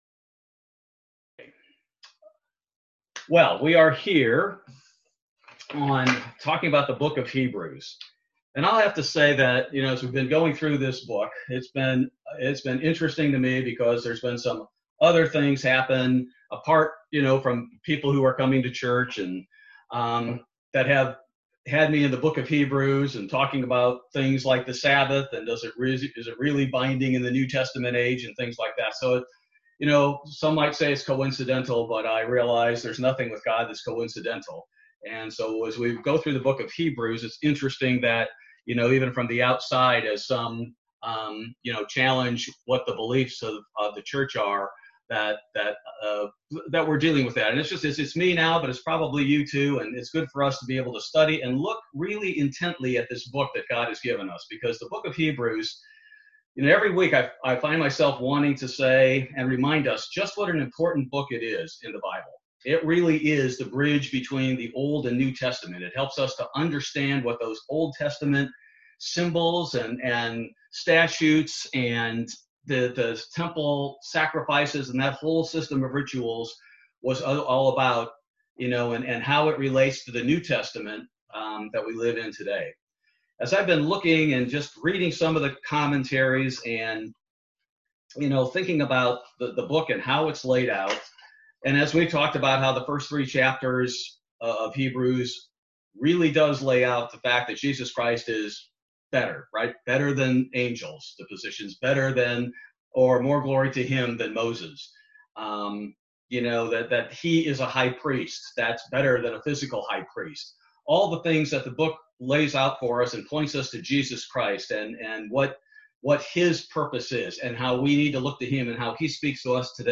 Bible Study November 25, 2020